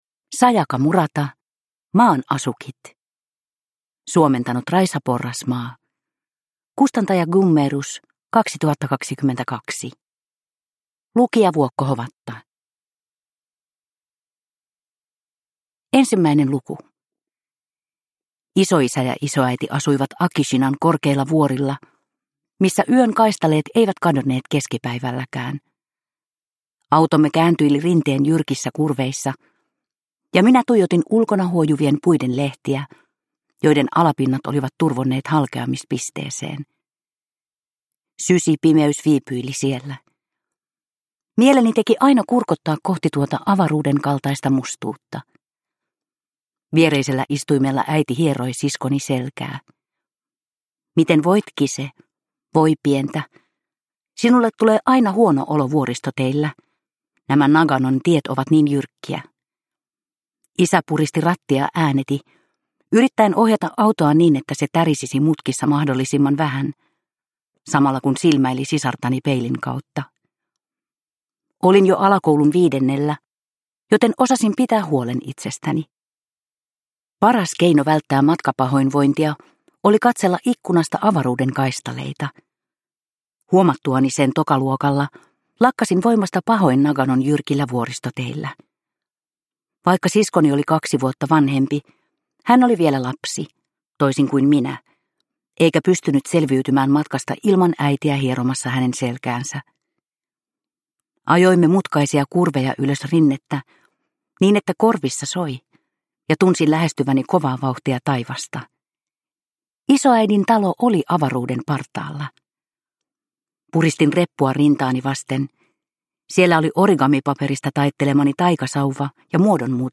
Maan asukit – Ljudbok – Laddas ner